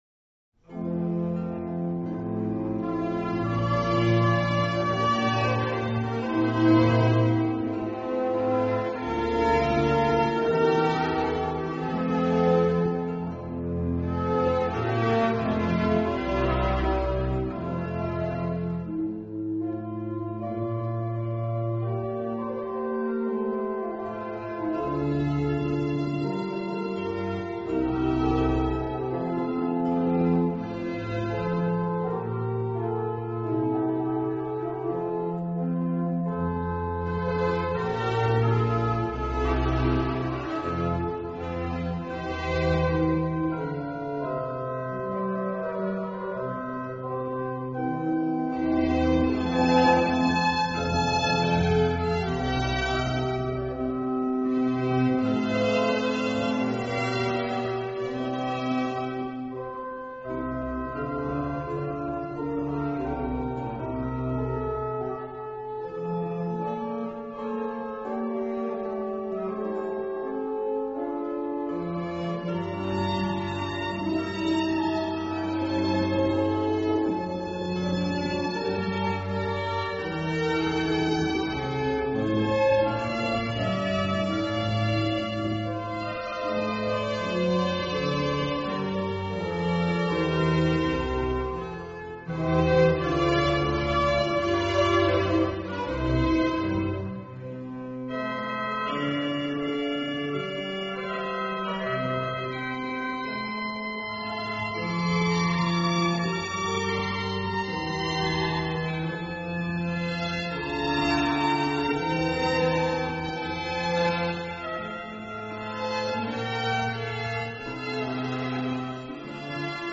音樂類型：古典音樂
清新且富有活力的詮釋是最迷人的地方。